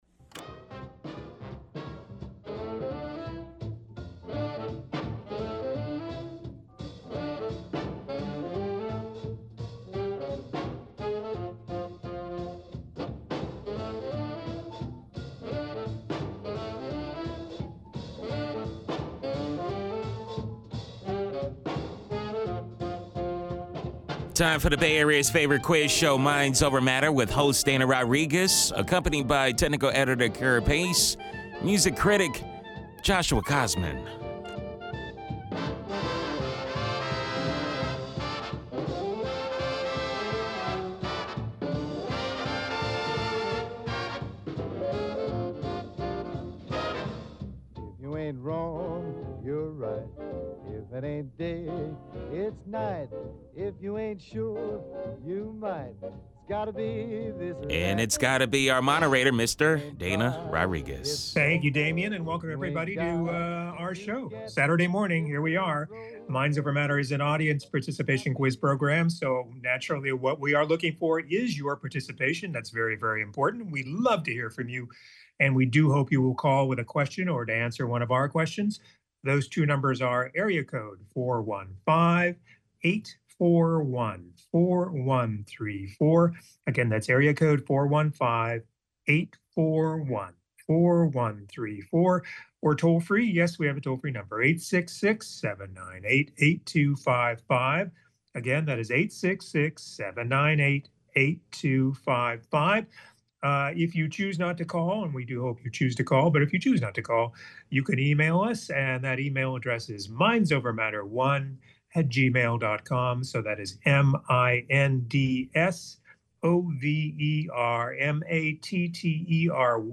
The Bay Area's Favorite Quiz Show Minds Over Matter!